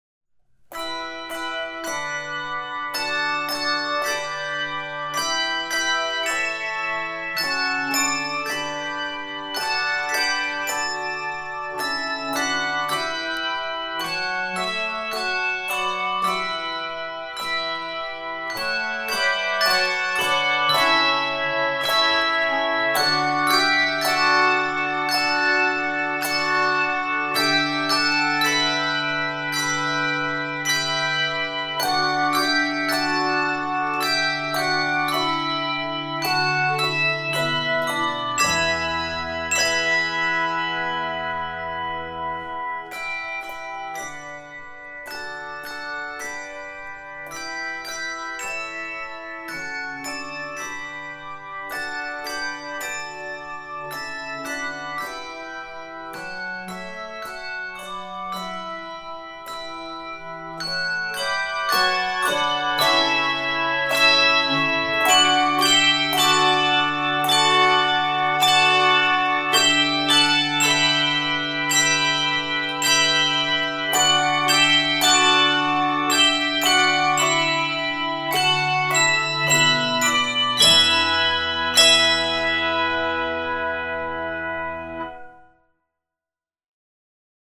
Key of e in the dorian mode.